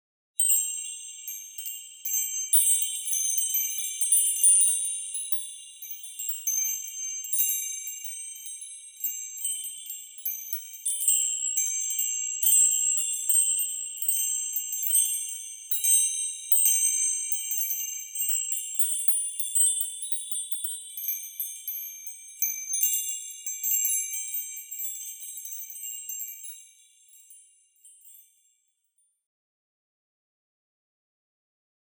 horror
Horror Wind Chimes Slight Reverb